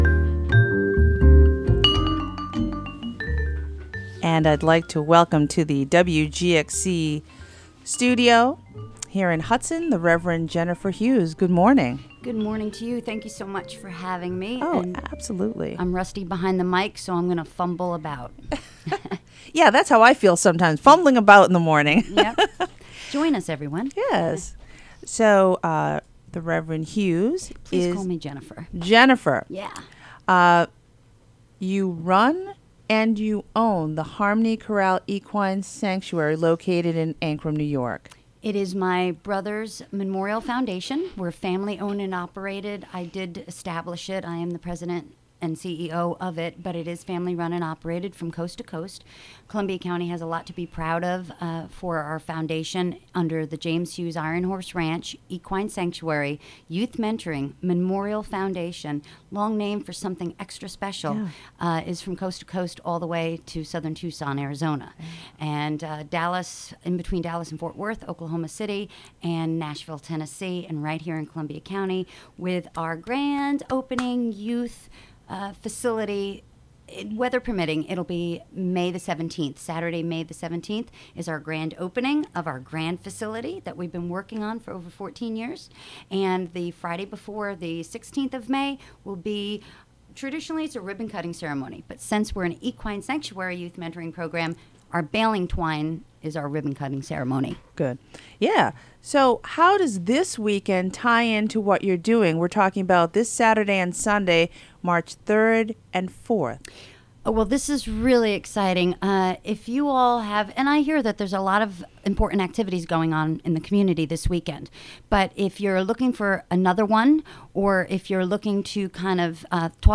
WGXC Morning Show Contributions from many WGXC programmers.